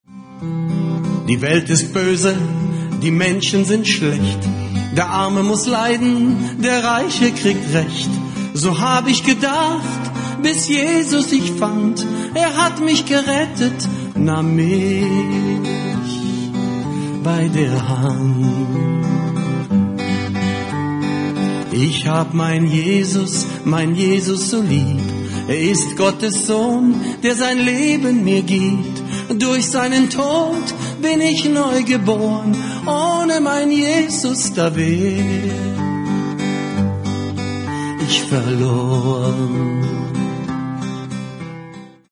Orchester mix
Unplugged
Karaoke mix